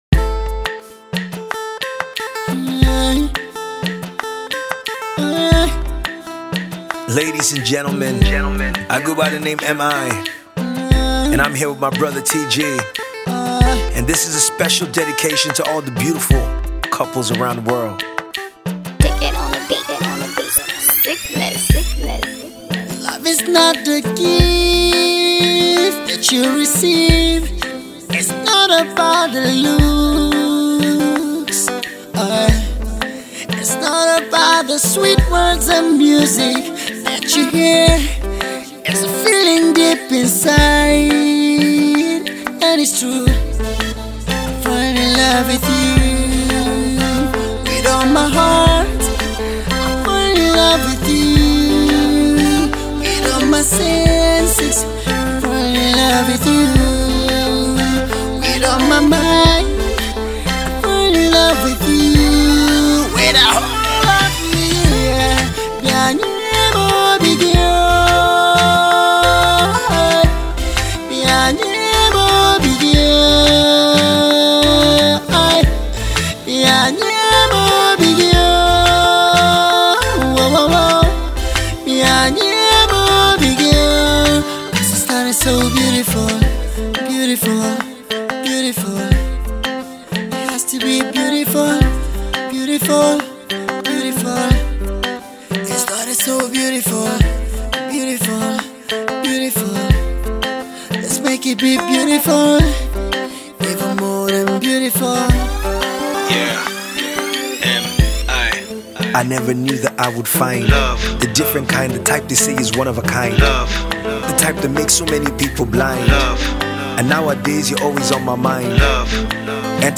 piano
live guitar